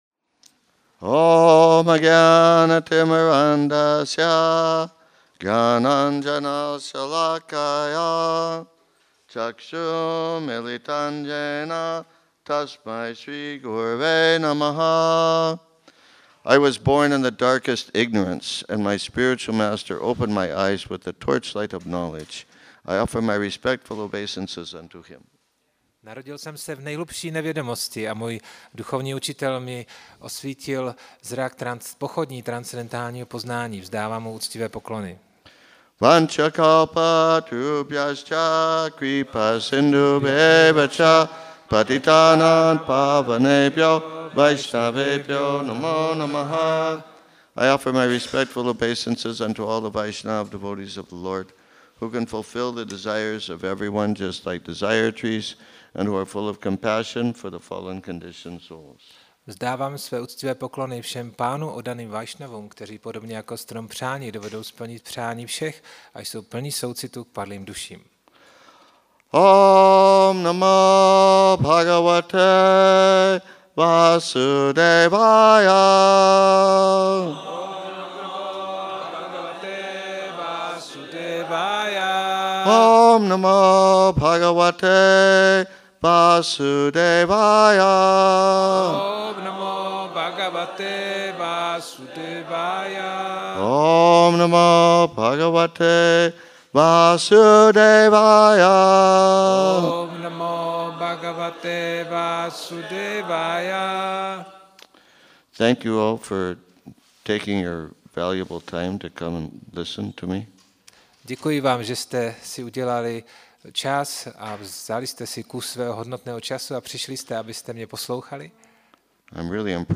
Otázky a odpovědi